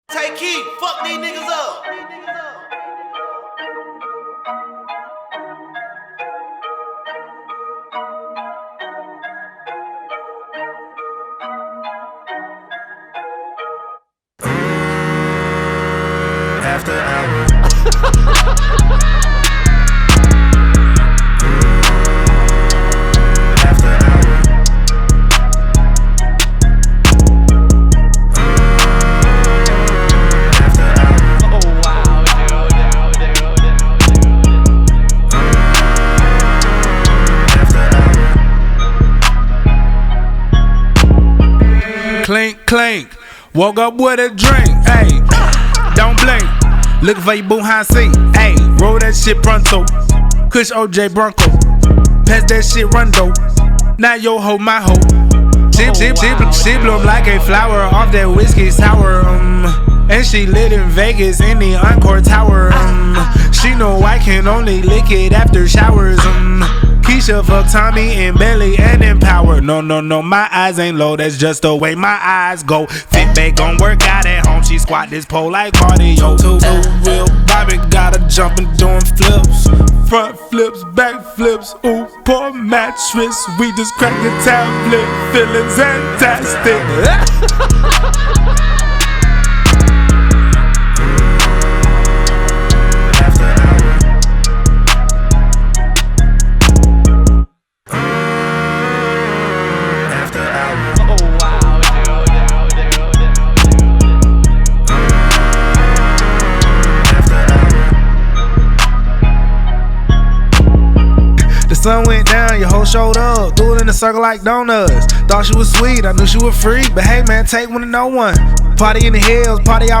today he furthers the course with this new bouncy track.